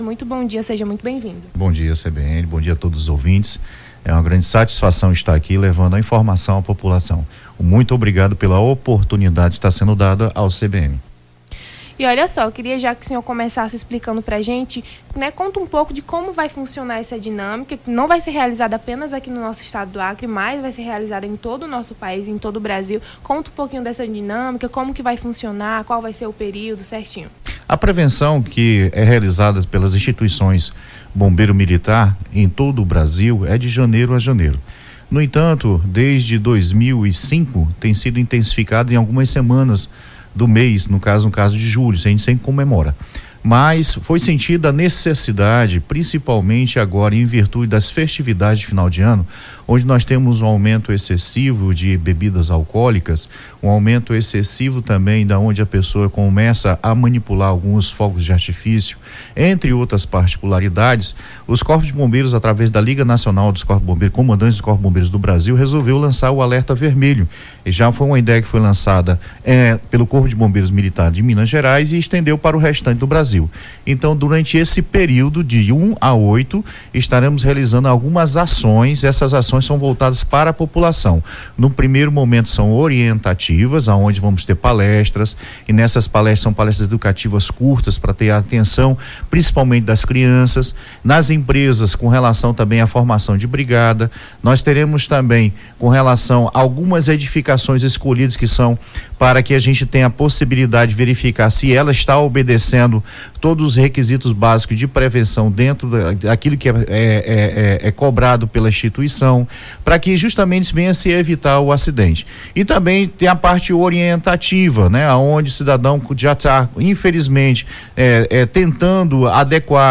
Nome do Artista - SONORA-ENTREVISTA-BOMBEIROS-_30.11.2023_.mp3